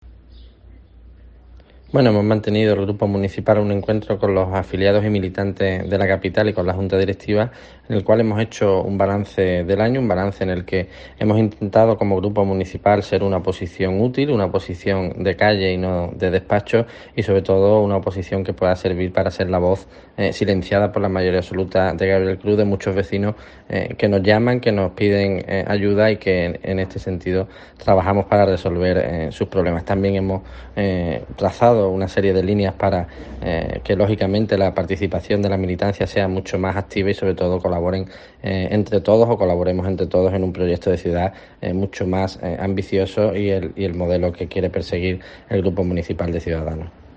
Guillermo García de Longoria, portavoz de Cs en el Ayuntamiento de Huelva